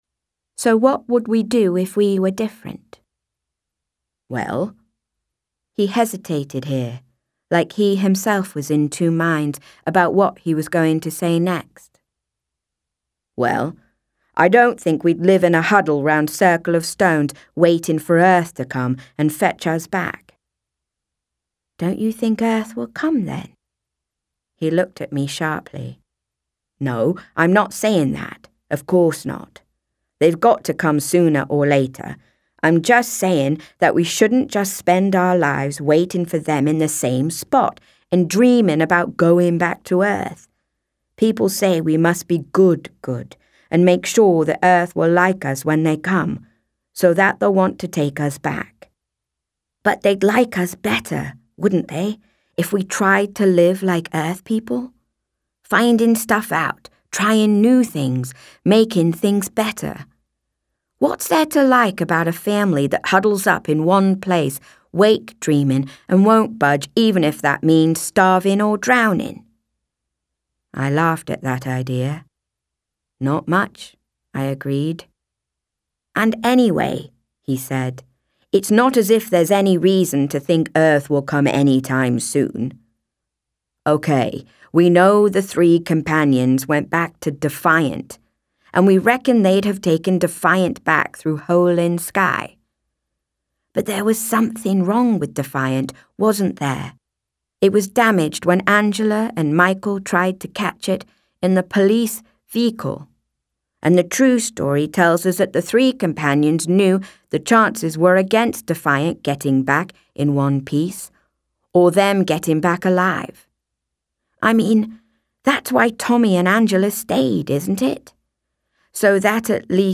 In an earlier post I mentioned that the 8 actors performing the new US audio book of Dark Eden have been working on a whole new Eden accent, not quite like any accent on Earth: a very ambitious and difficult task to take on.